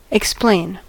explain: Wikimedia Commons US English Pronunciations
En-us-explain.WAV